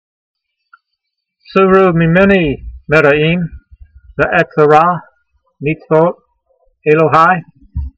Sound (Psalm 119:115) Transliteration: soo roo - mee men ee mer(d)ay' eem , ve ' e ' tse' r a meets v ot e'lo' h ai Vocabulary Guide: Get away from me evildoers , as for me I will keep the commandment s of my God . Translation: Get away from me evildoers, as for me I will keep the commandments of my God.